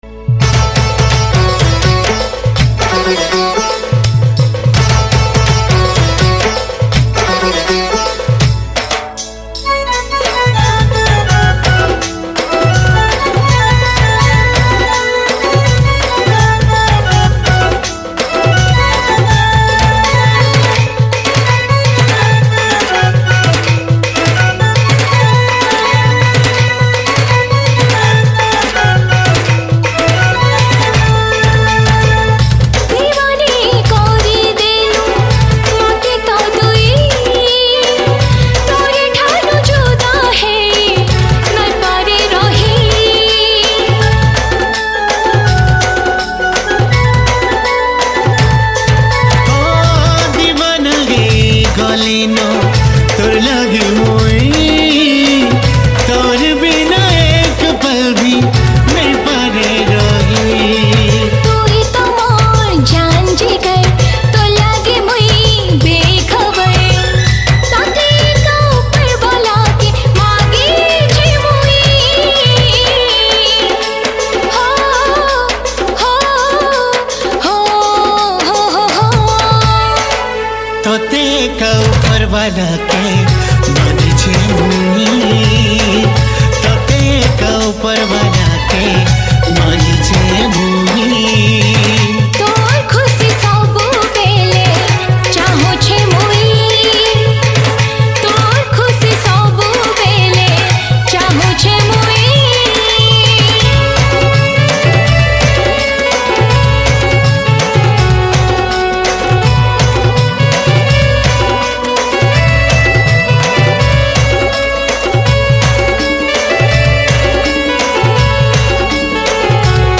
Category: New Sambalpuri Folk Song 2021